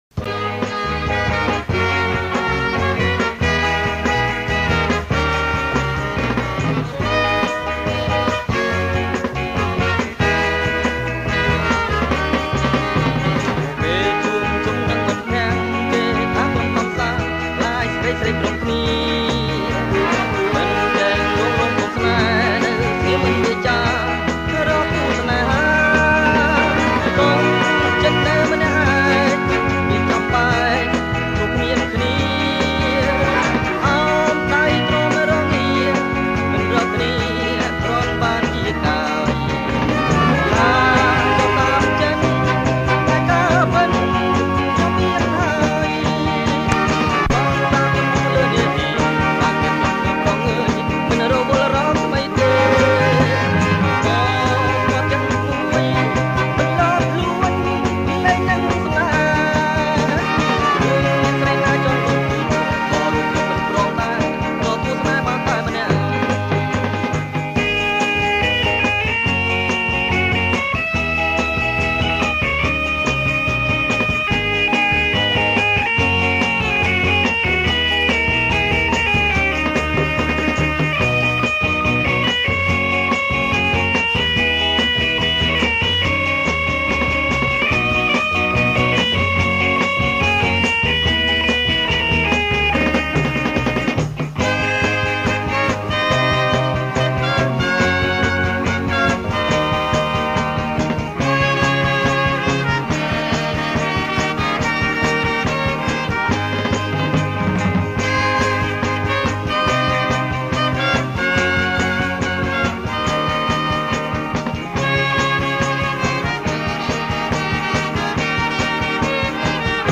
ចេញផ្សាយជាកាស្សែត (Cassette)
ប្រគំជាចង្វាក់